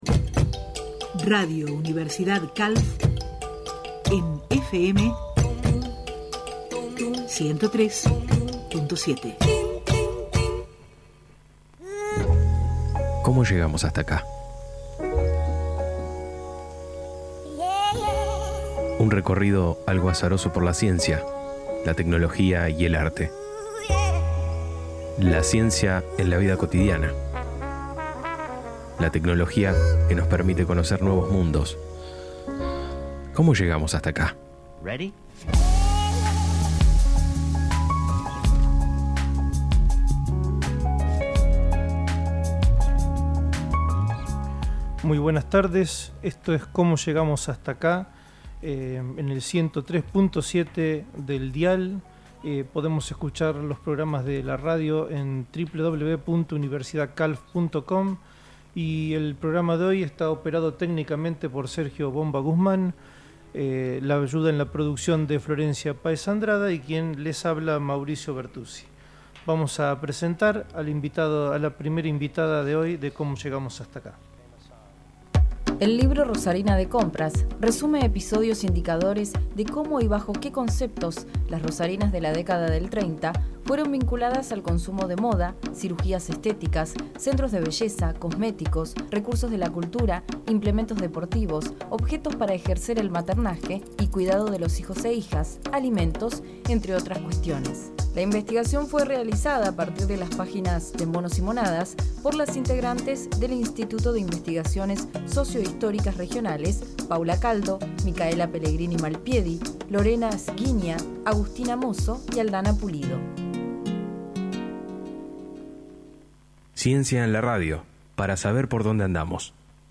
El programa de divulgación de la ciencia y la tecnología «Cómo llegamos hasta acá» se emite los miércoles a partir de las 13.30 hs en la FM 103.7 Universidad-Calf.